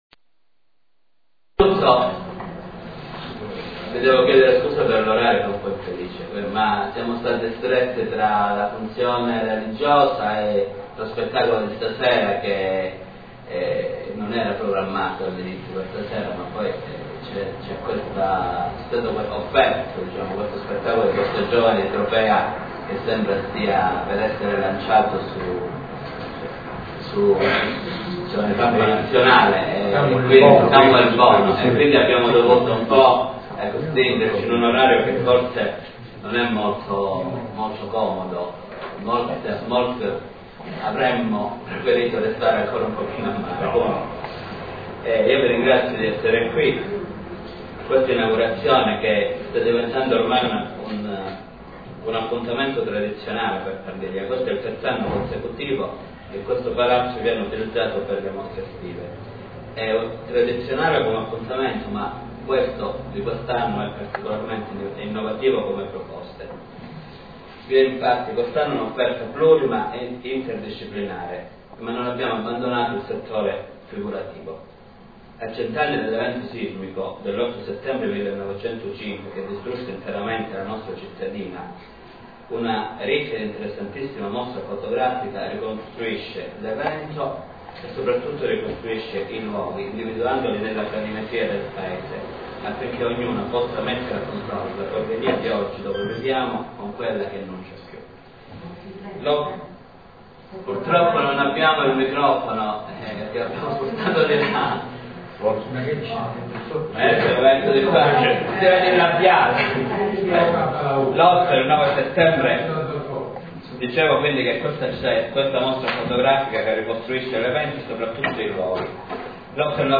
Inaugurazione  Mostra: "Terremoto e Ricostruzione 1905-1935"
Mostra: "Terremoto e Ricostruzione 1905-1935"  inaugurata dal Sindaco Vincenzo Calzona